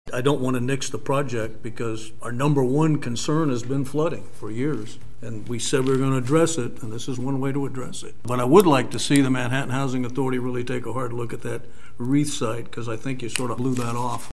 That’s Commissioner Wynn Butler.